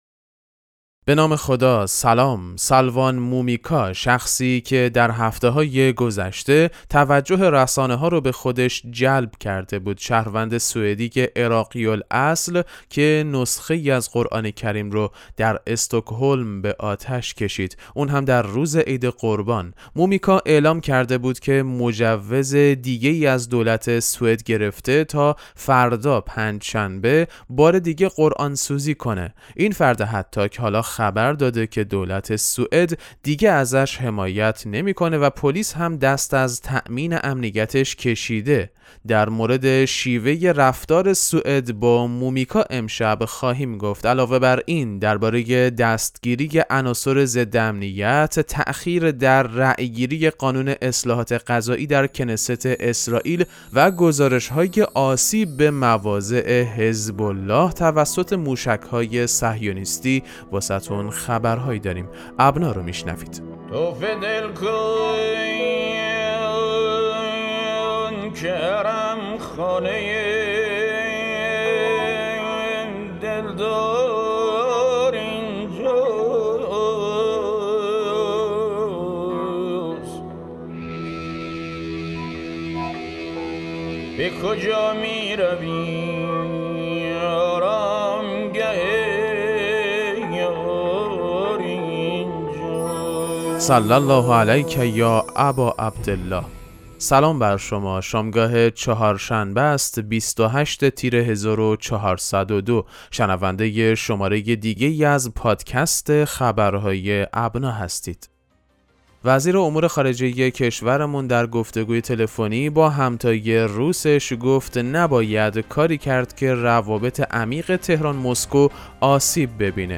پادکست مهم‌ترین اخبار ابنا فارسی ــ 28 تیر 1402